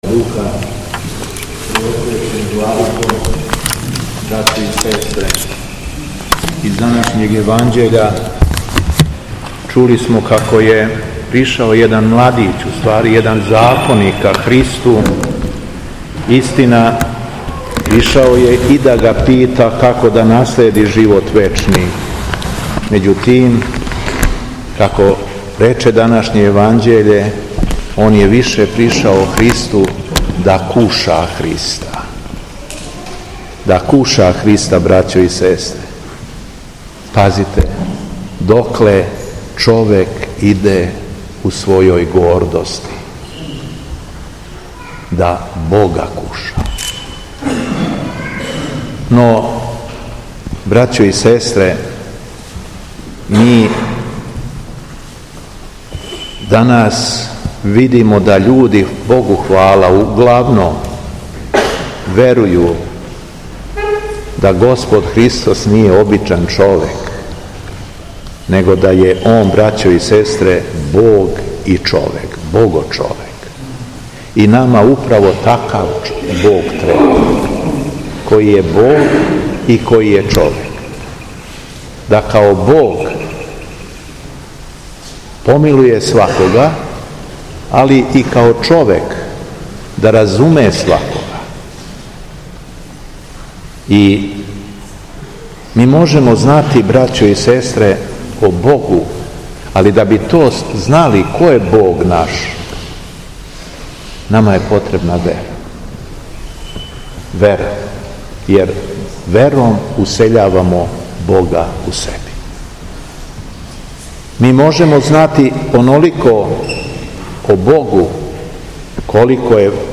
СВЕТА АРХИЈЕРЕЈСКА ЛИТУРГИЈА У ХРАМУ СВЕТОГ ВЕЛИКОМУЧЕНИКА ДИМИТРИЈА У ЛАЗАРЕВЦУ - Епархија Шумадијска
Беседа Његовог Високопреосвештенства Митрополита шумадијског г. Јована
Осврћући се на речи данашњег Јеванђеља Митрополит Јован је надахнуто поучавао окупљене вернике: